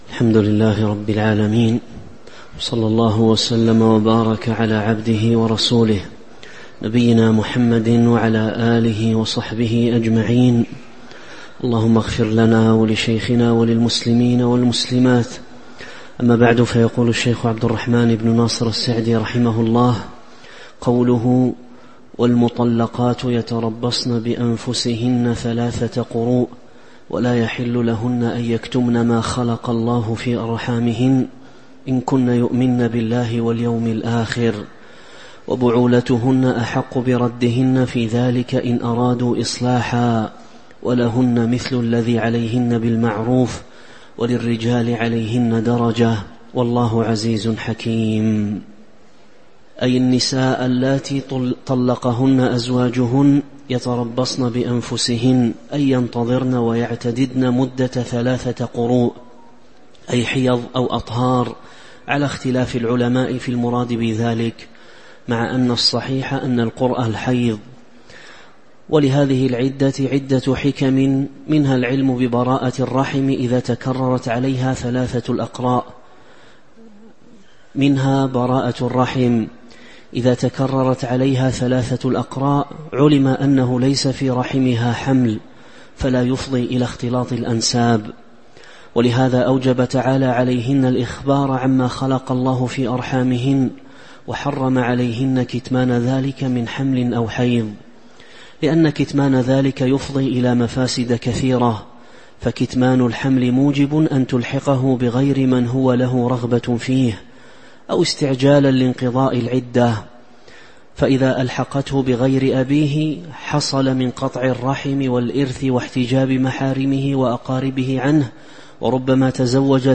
تاريخ النشر ١٤ شعبان ١٤٤٦ هـ المكان: المسجد النبوي الشيخ: فضيلة الشيخ عبد الرزاق بن عبد المحسن البدر فضيلة الشيخ عبد الرزاق بن عبد المحسن البدر تفسير سورة البقرة من آية 228 (094)